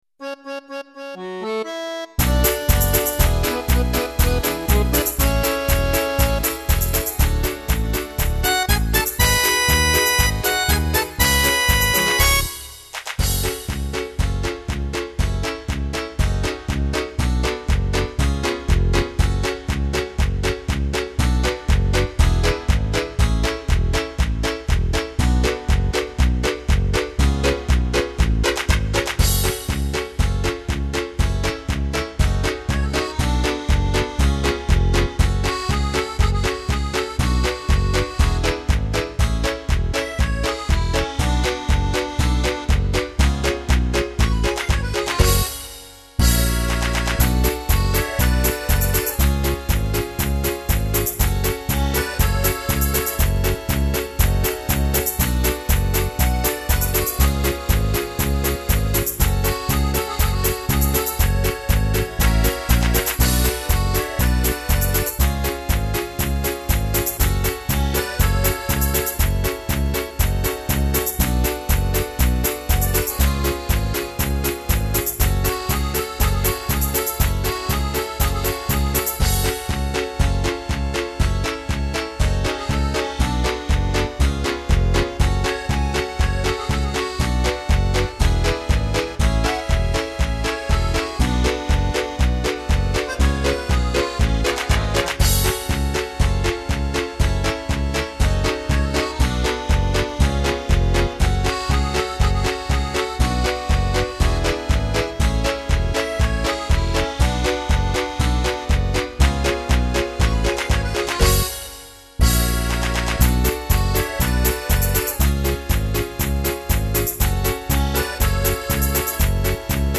минусовка версия 27523